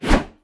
wpn_swing_axe_001.wav